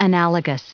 added pronounciation and merriam webster audio
51_analogous.ogg